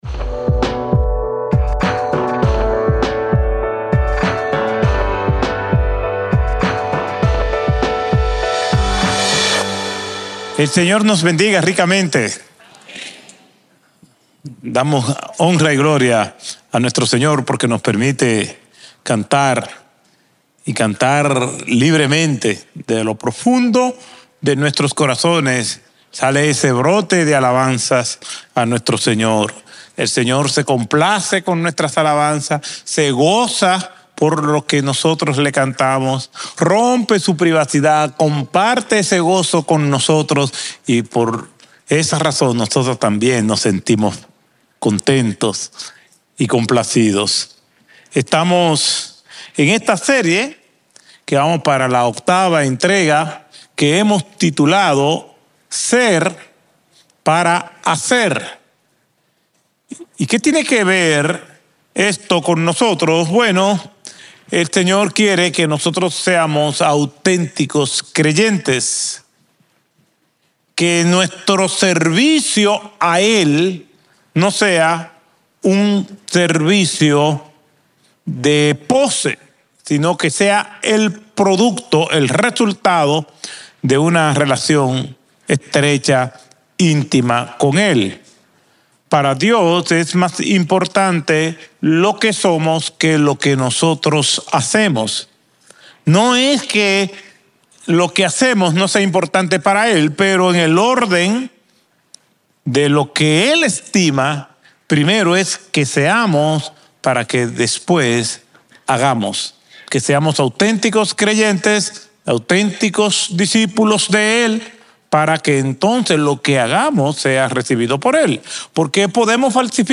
Un mensaje de la serie "Ser para hacer."